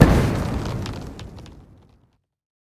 poison-capsule-explosion-4.ogg